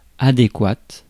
Prononciation
Prononciation France: IPA: /a.de.kwat/ Le mot recherché trouvé avec ces langues de source: français Les traductions n’ont pas été trouvées pour la langue de destination choisie.